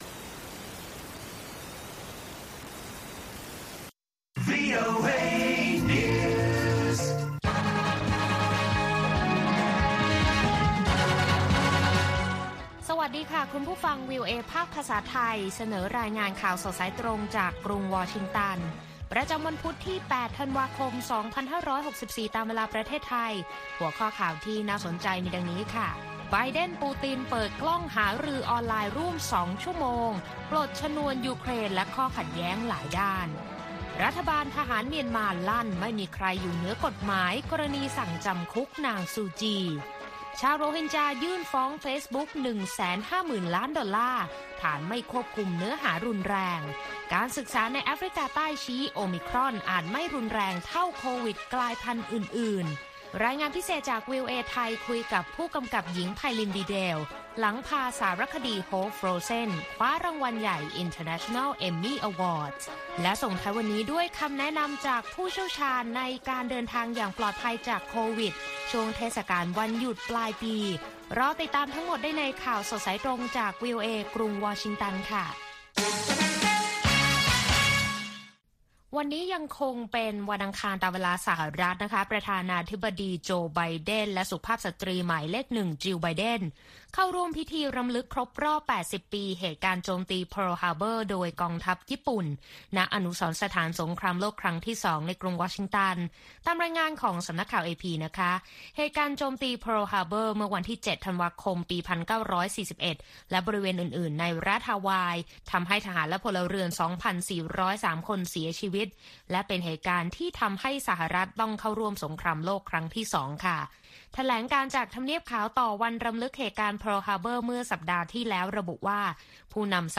ข่าวสดสายตรงจากวีโอเอ วันพุธ ที่ 8 ธันวาคม 2564